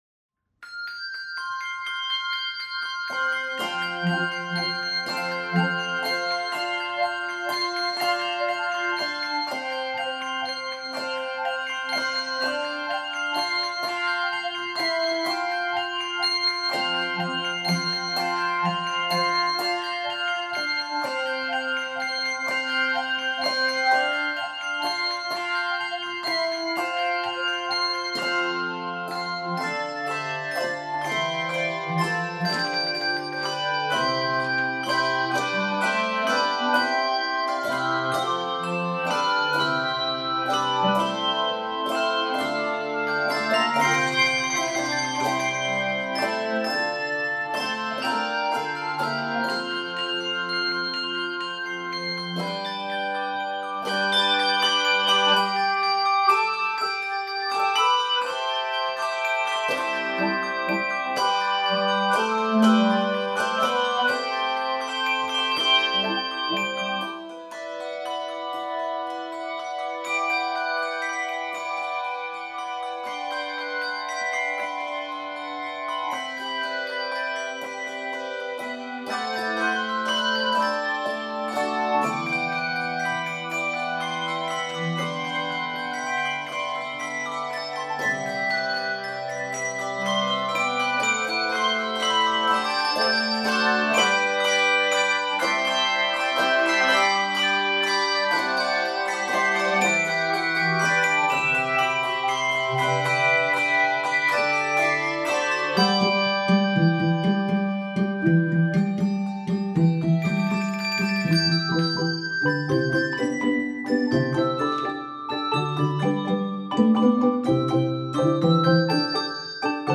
This energetic setting